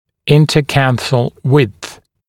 [ˌɪntə’kænθl wɪdθ][ˌинтэ’кэнсл уидс]ширина между углами глазной щели